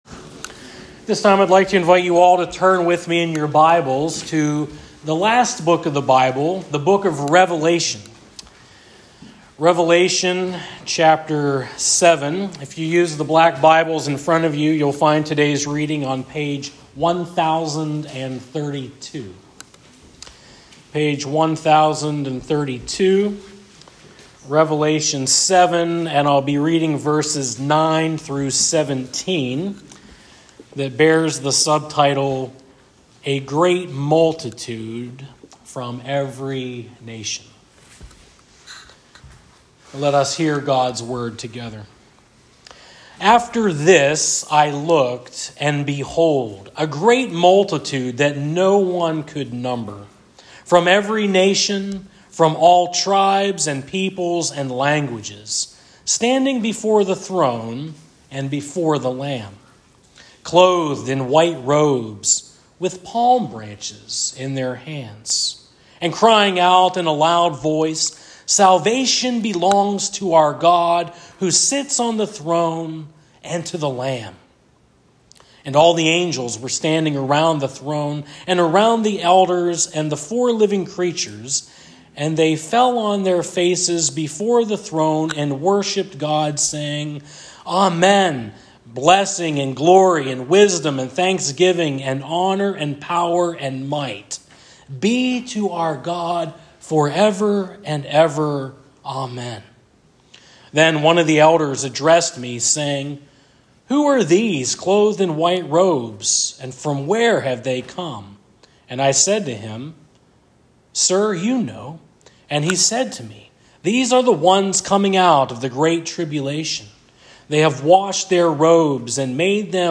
Sermons | Franklin United Church